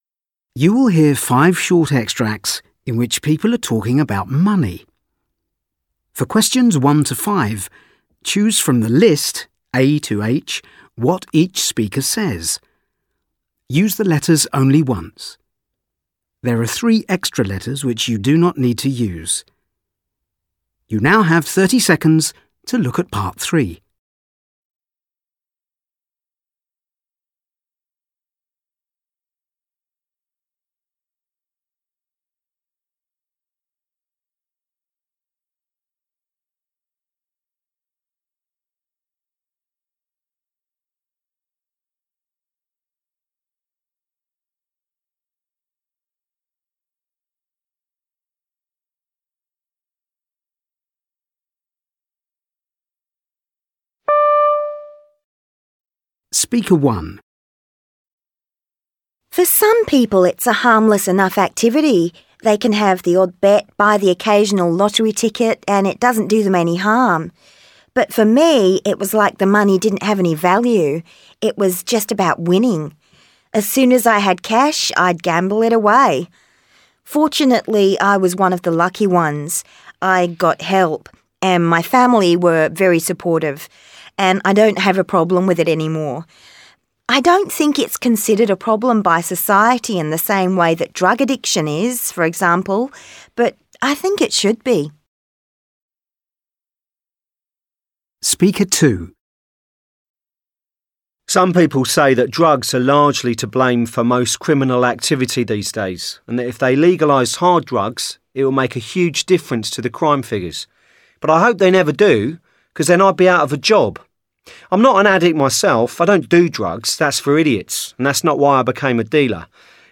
You will hear five different people talking about money.